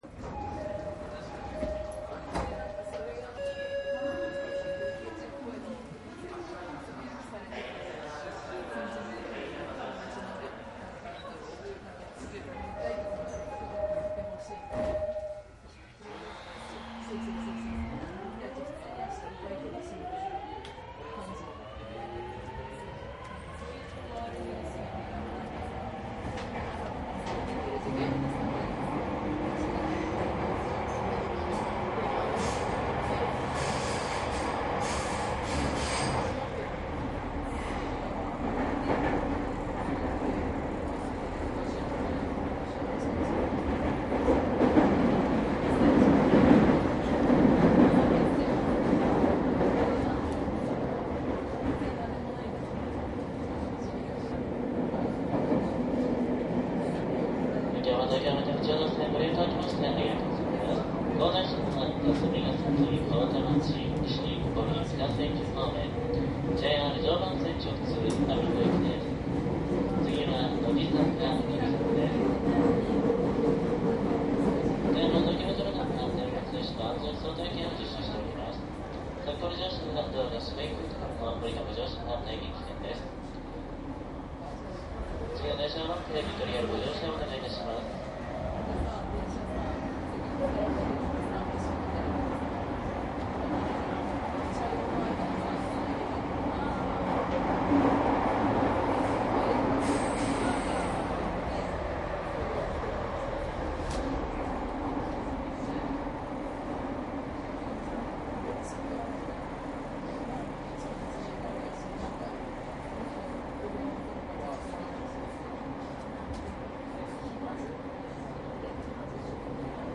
♪JR209系1000番台 千代田線走行音 CDです。
自動放送はありませんので車掌による案内になります。
いずれもマイクECM959です。TCD100の通常SPモードで録音。
実際に乗客が居る車内で録音しています。貸切ではありませんので乗客の会話やが全くないわけではありません。